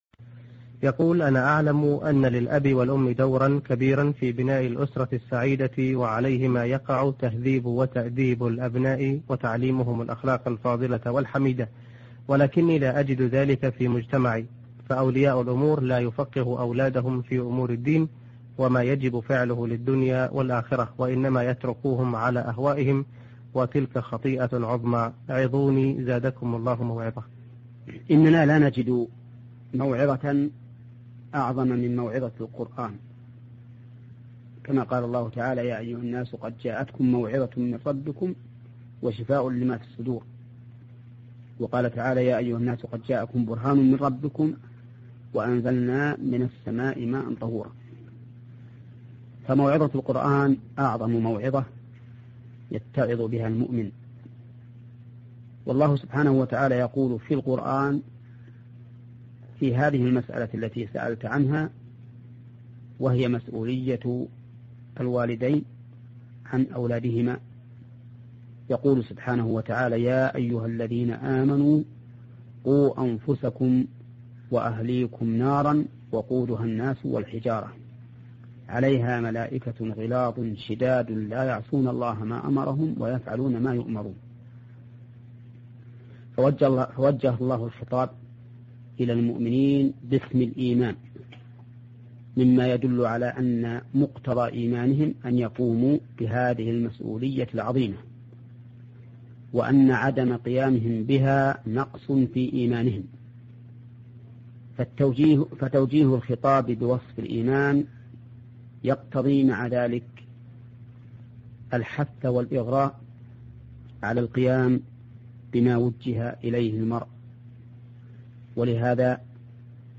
موعظة للآباء في تربية الأبناء